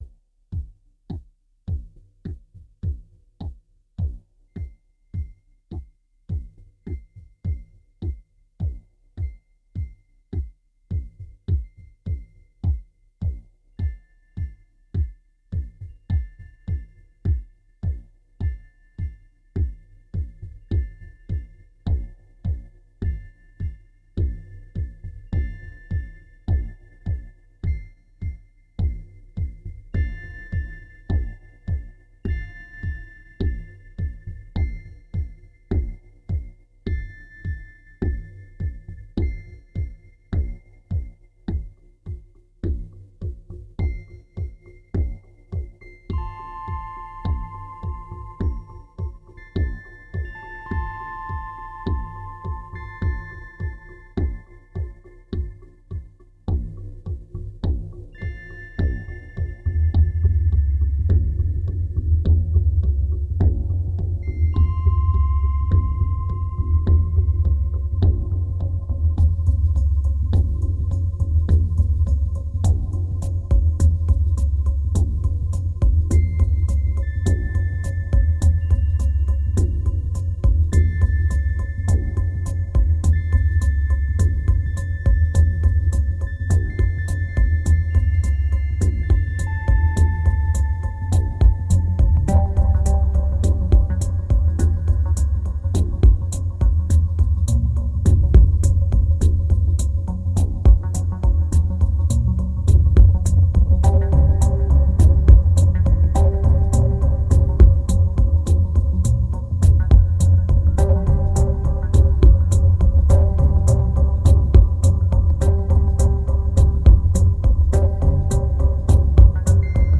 ambient album
2294📈 - -10%🤔 - 104BPM🔊 - 2010-11-09📅 - -553🌟